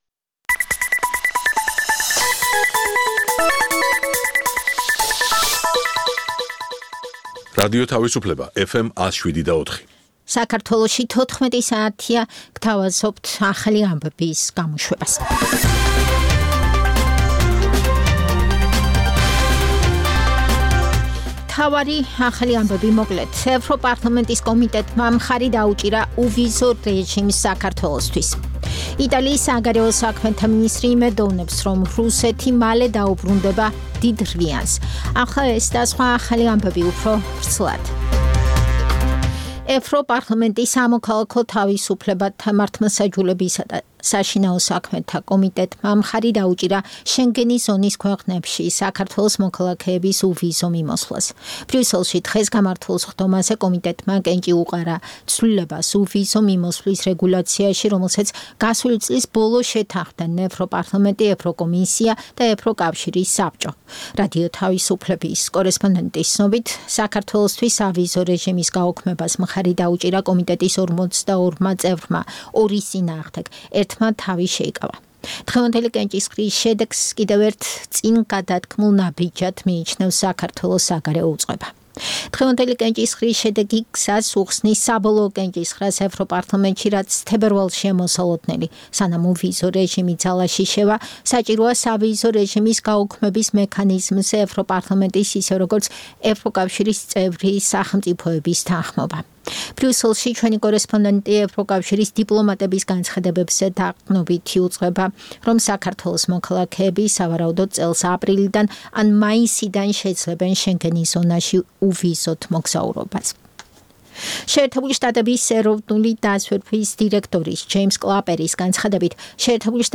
ახალი ამბები (რადიო თავისუფლება) + Music Mix ("ამერიკის ხმა")